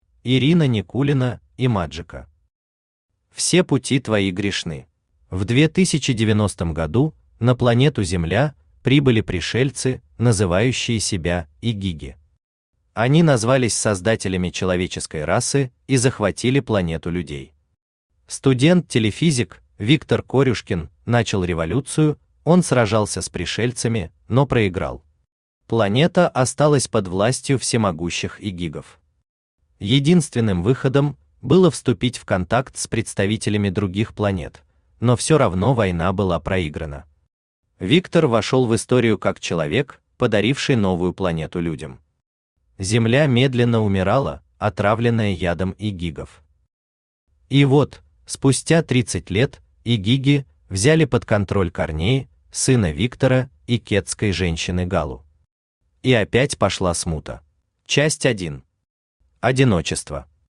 Аудиокнига Все пути твои грешны | Библиотека аудиокниг